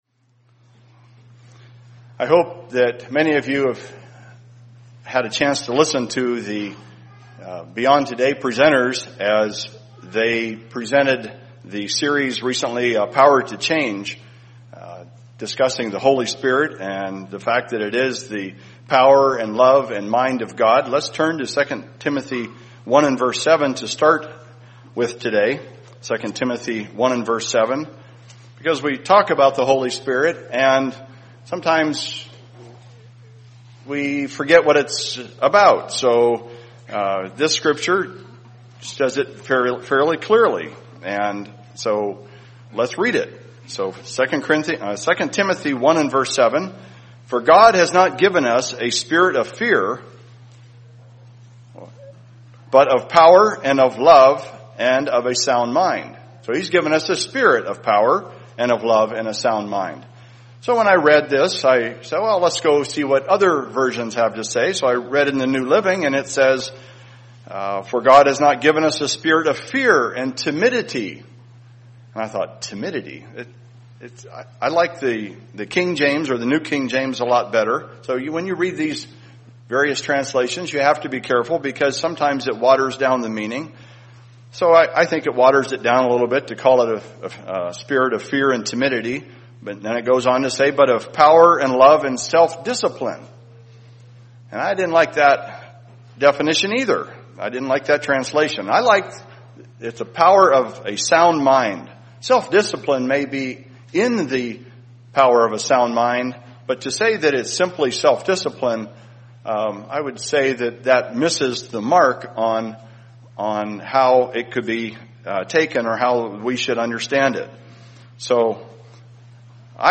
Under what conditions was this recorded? Sermon during afternoon service on Pentecost, Sunday, June 8, 2014 in Spokane, Washington How does God's power, the Holy Spirit, come into us?